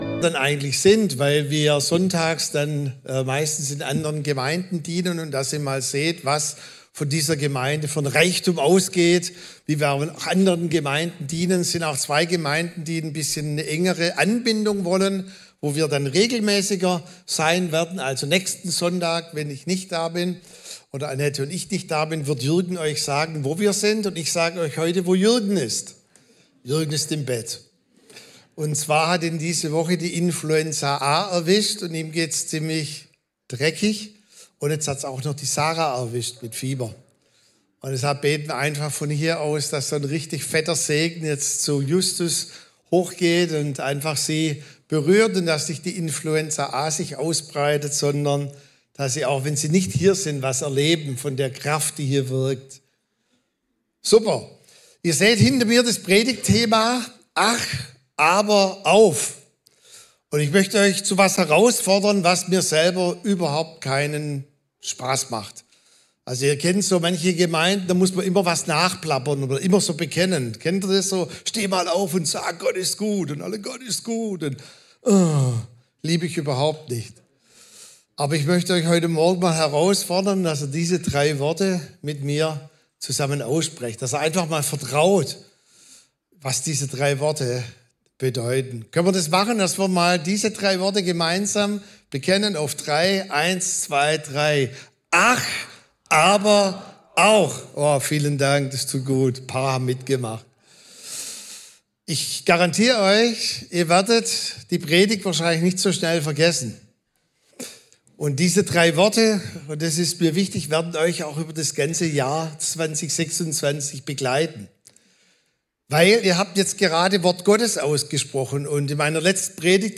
Sonntagspredigten